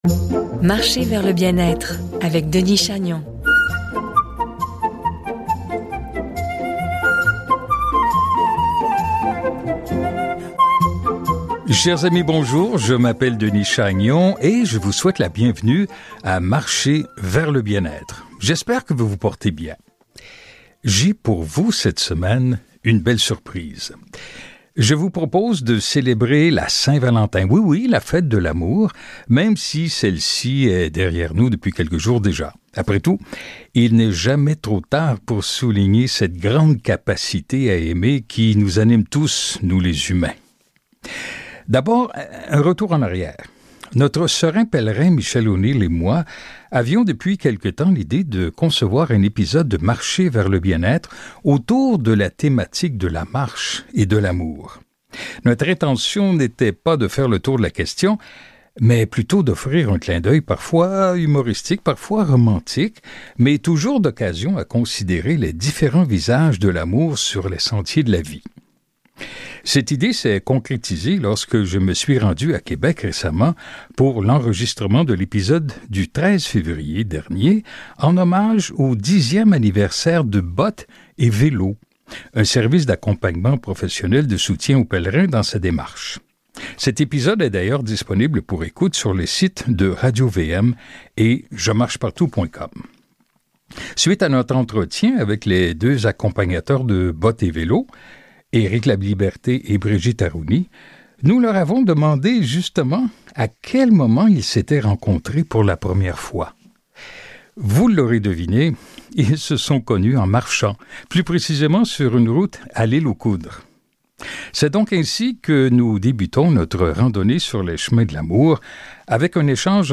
Suivront deux entretiens, l’un portant sur le film Alex marche à l’amour et l’autre sur un chapitre ( L’amour en chemin ) du livre Immortelle randonnée – Compostelle malgré moi , de Jean-Christophe Rufin. Et le tout habillé de très belles chansons !